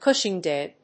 cushingoid.mp3